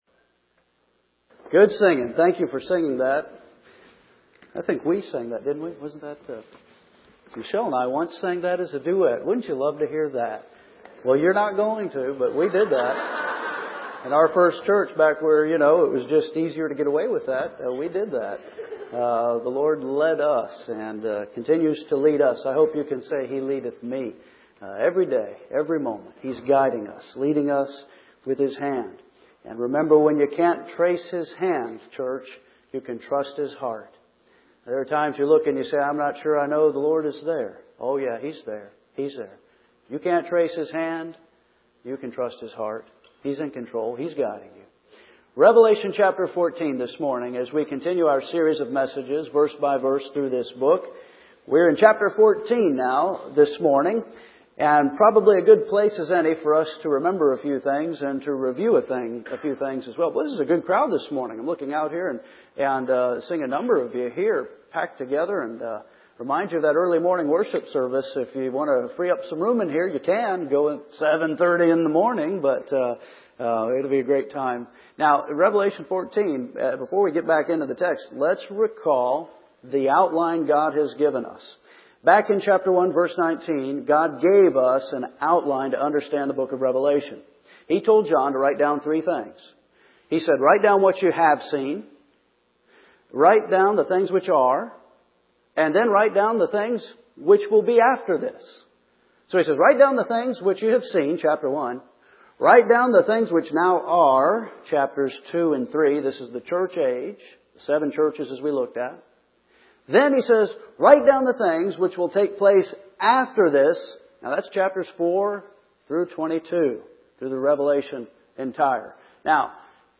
First Baptist Church, Henderson KY